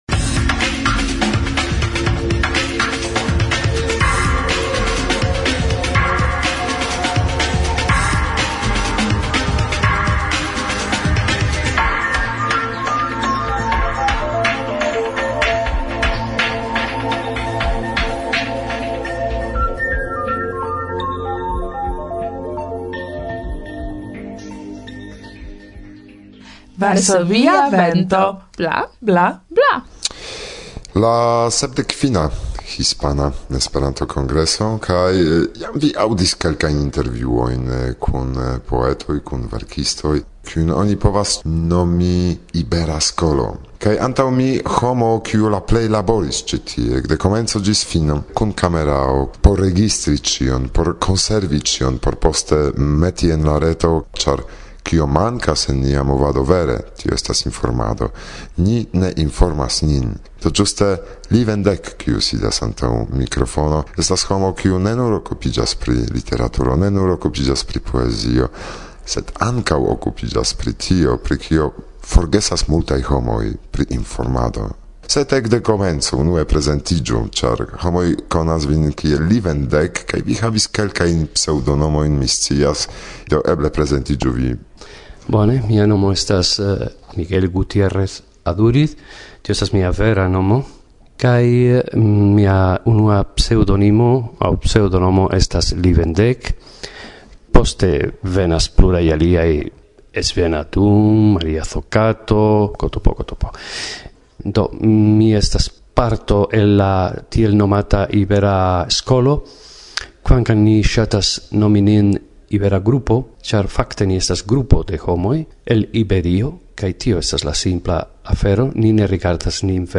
La unua intervjuo